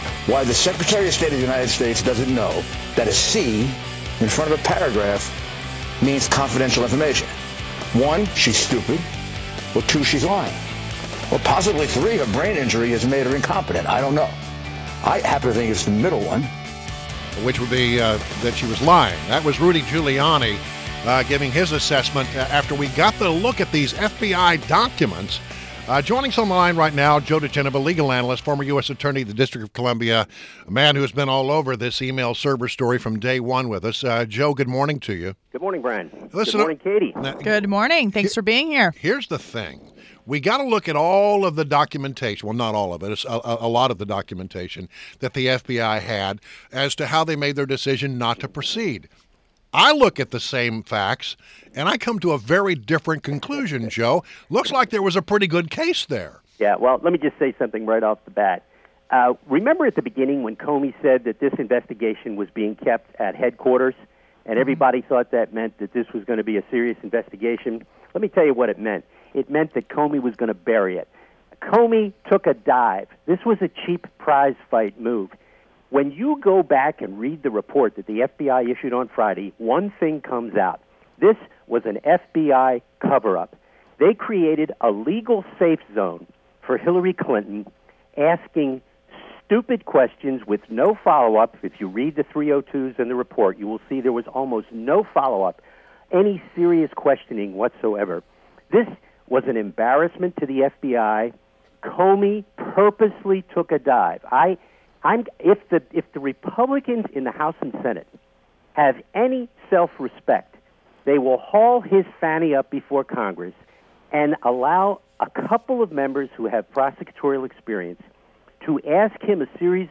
WMAL Interview - JOE DIGENOVA - 09.06.16